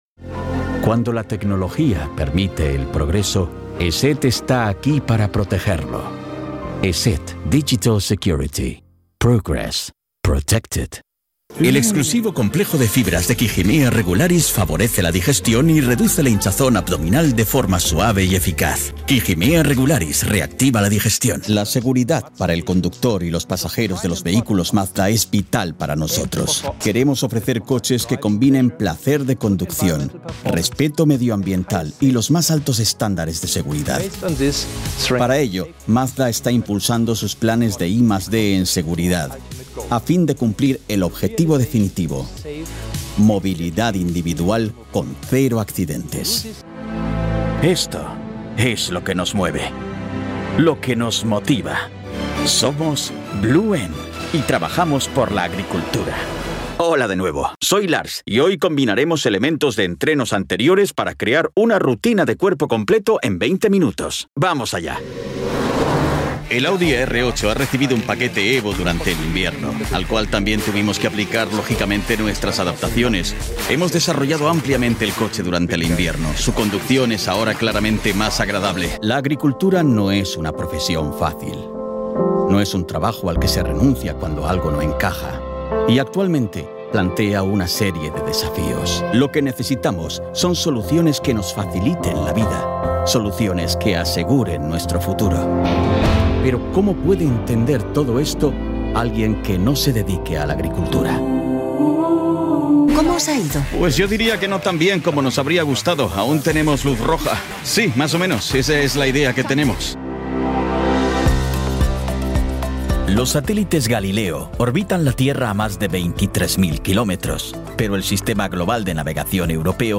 Commercial Demo
Neumann U-87, Pro Tools, Audition, Nuendo, Audient & UAudio interfaces.
ConversationalWarmBrightConfidentCharismaticUpbeat